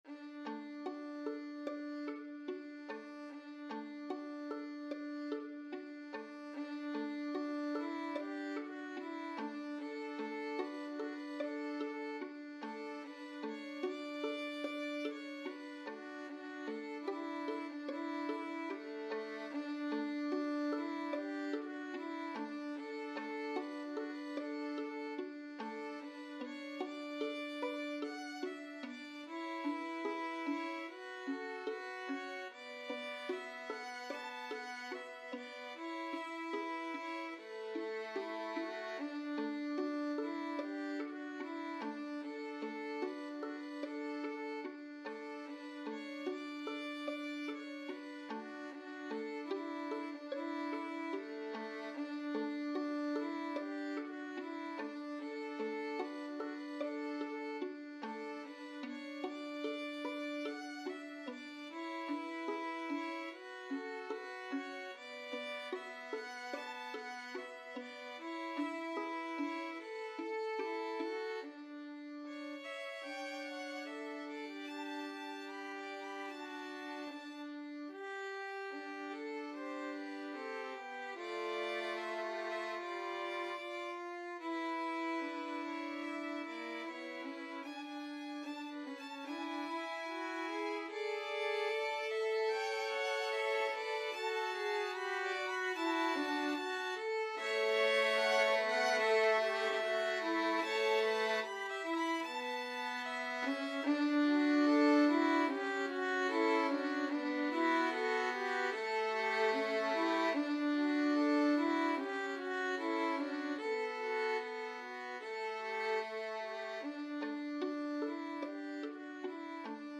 4/4 (View more 4/4 Music)
Violin Trio  (View more Intermediate Violin Trio Music)
Classical (View more Classical Violin Trio Music)